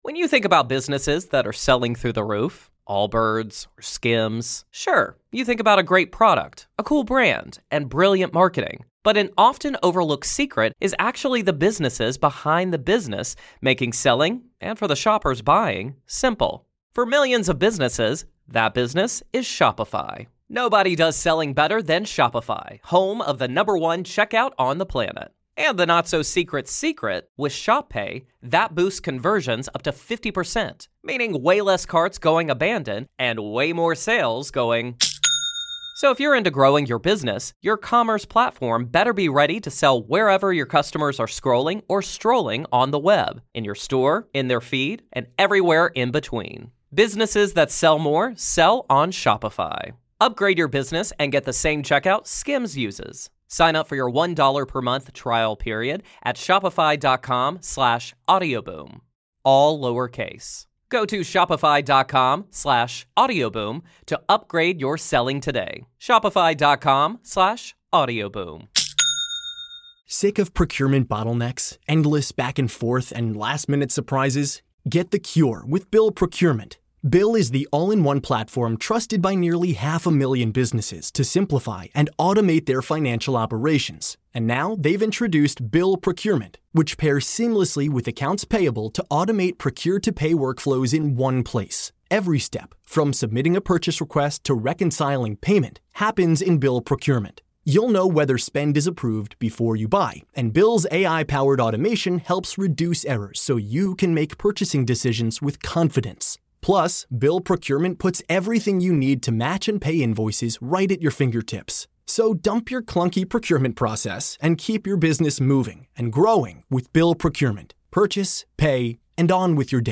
2025 Mortgage Rates Explained – LIVE Event for Home Buyers